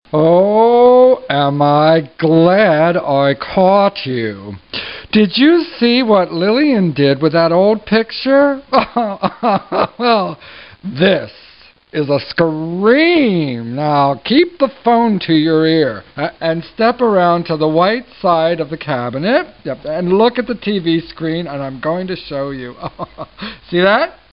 The opening lines are